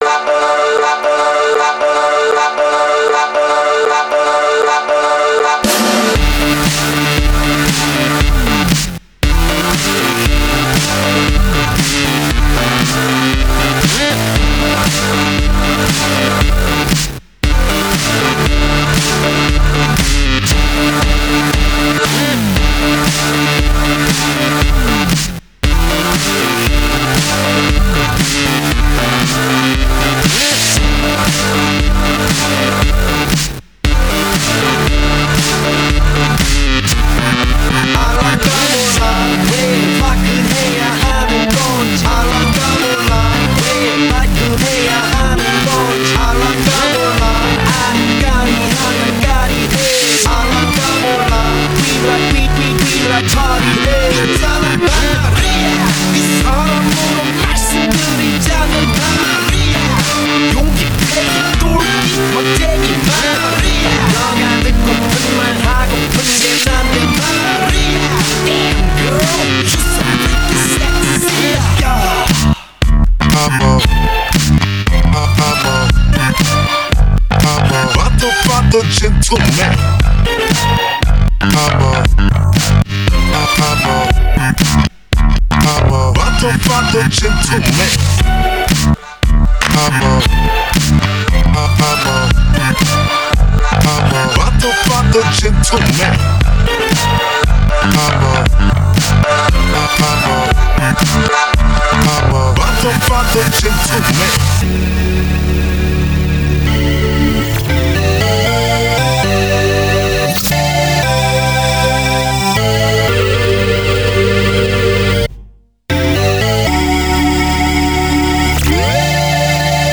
Mashup
Remix